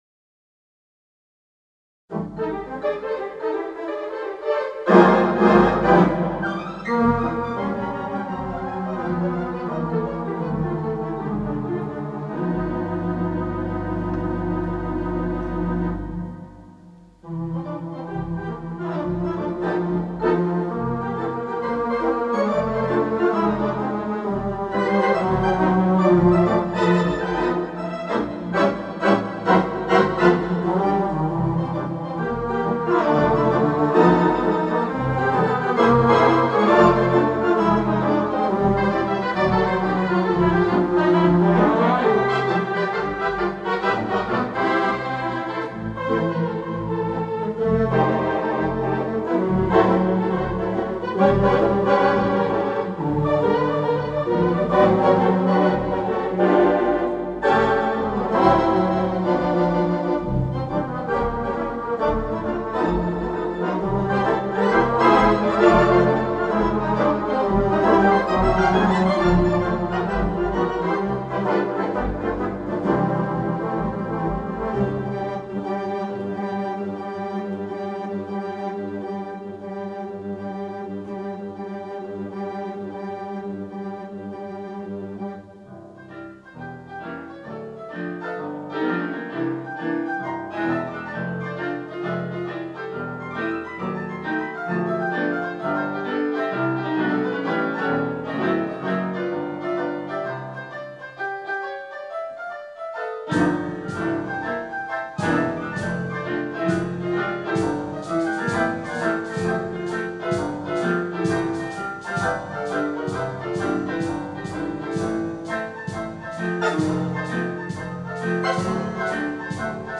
Digital Theatre Organ
The Second Concert